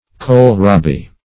Search Result for " kohl-rabi" : The Collaborative International Dictionary of English v.0.48: Kohl-rabi \Kohl"-ra`bi\, n.; pl.